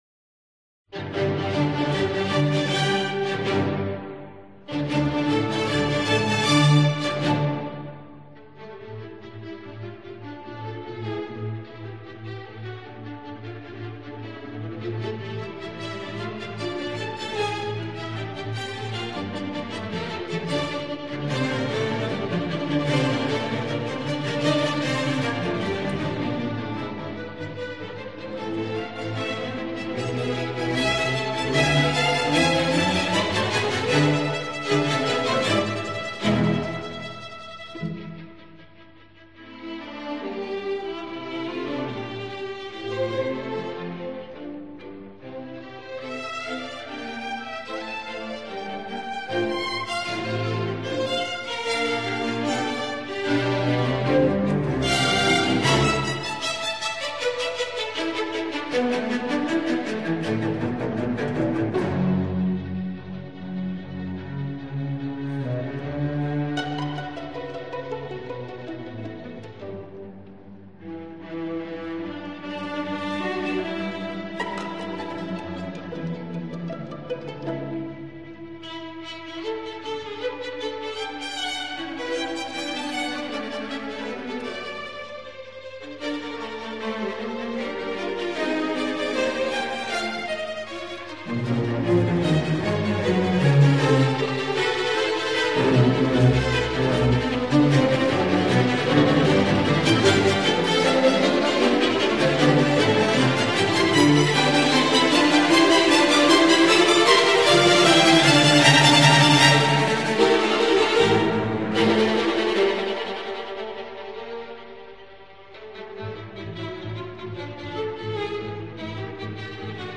Orchestre de chambre